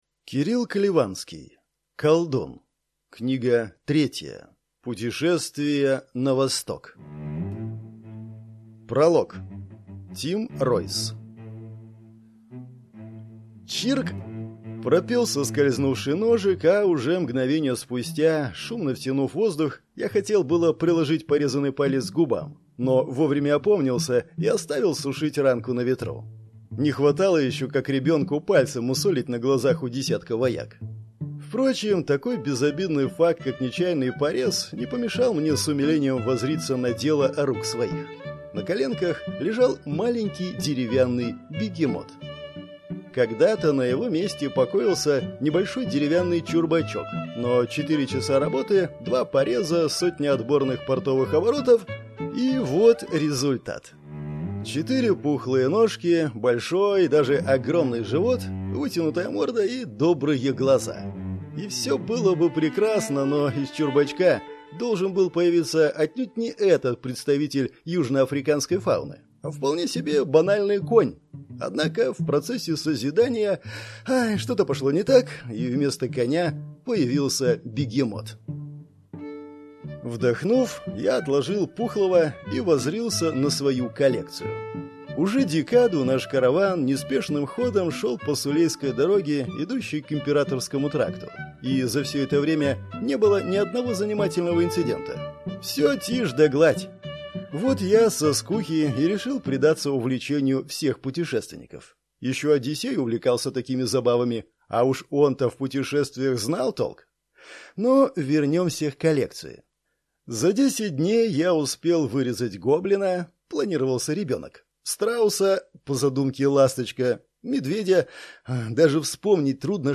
Аудиокнига Колдун. Путешествие на восток | Библиотека аудиокниг
Прослушать и бесплатно скачать фрагмент аудиокниги